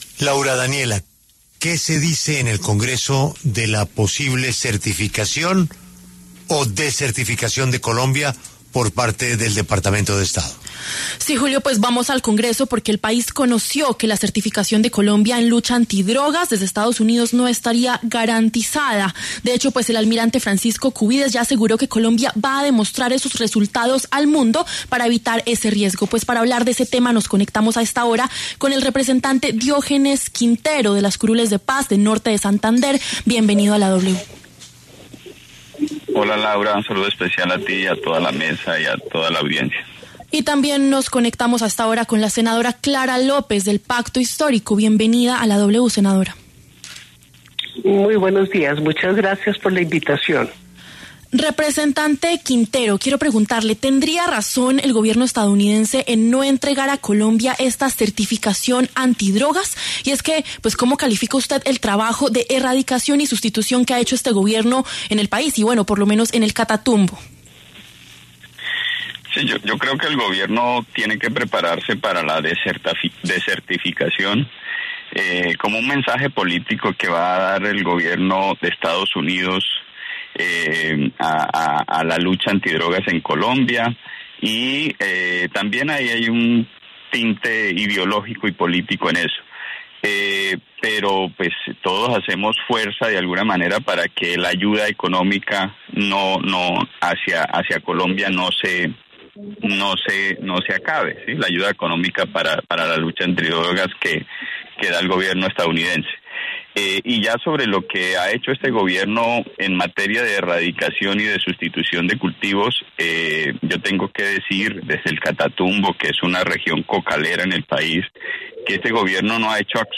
Los congresistas Diógenes Quintero, de las Curules de Paz, y la senadora Clara López, del Pacto Histórico, hablaron en La W.